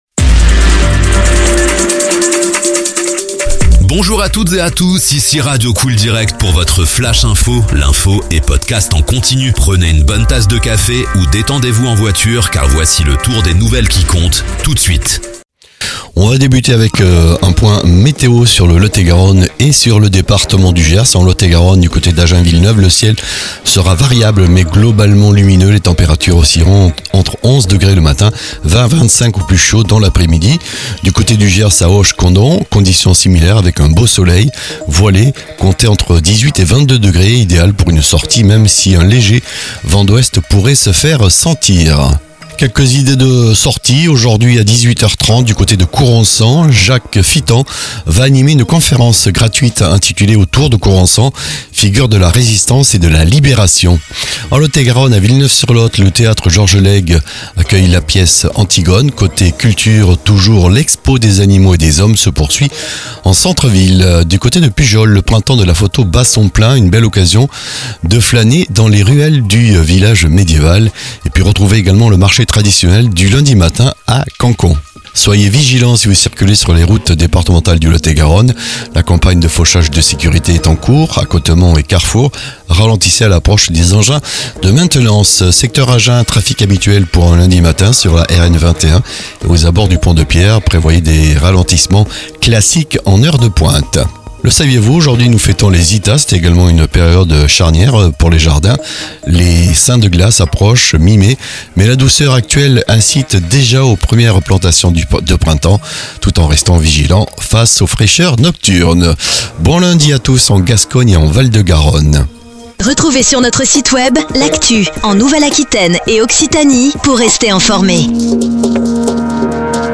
Flash infos 27/04/2026
L'infos sur radio COOL DIRECT de 7h à 21h